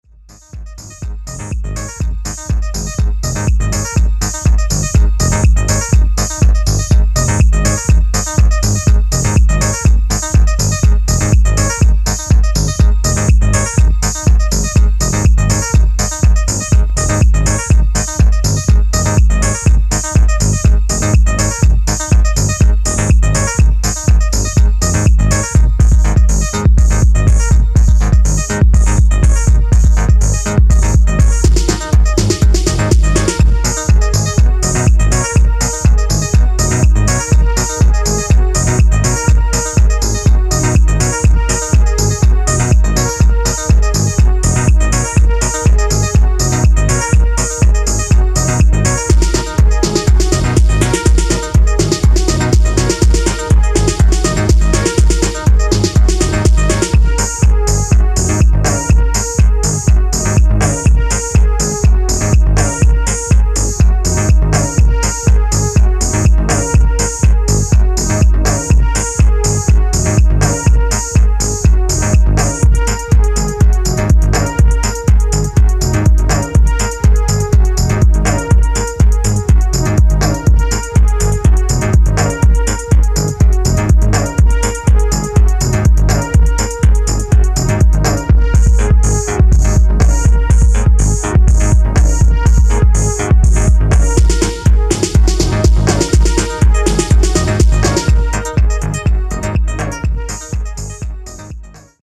催眠的ミニマルリフ、畳み掛けるスネアによる素朴にしてファンキー